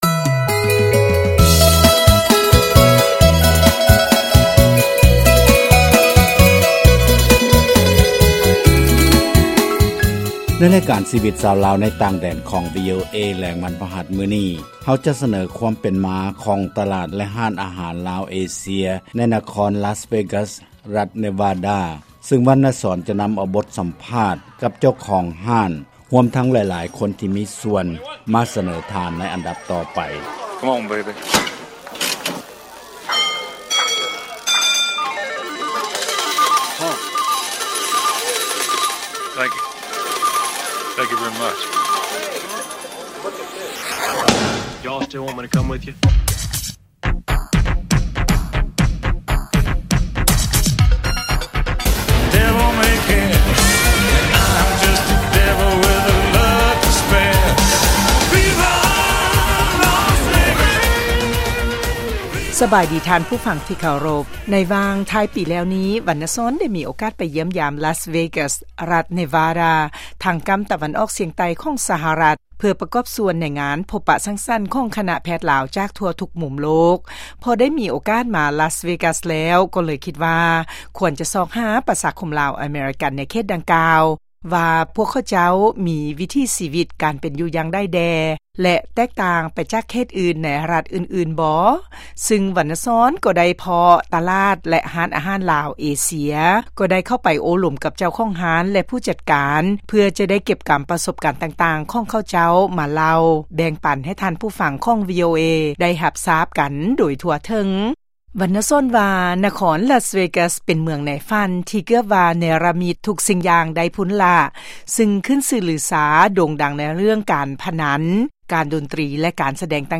ການສໍາພາດ-ສະມາຊິກຕະຫຼາດລາວ-ເອເຊຍ